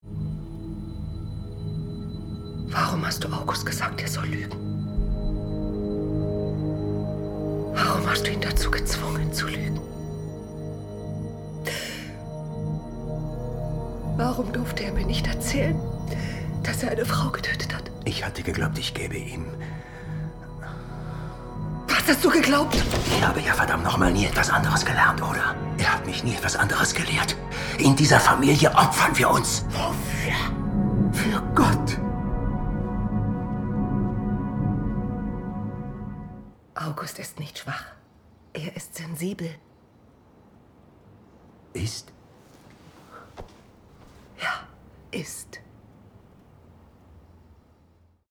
Synchron / Serienhauptrolle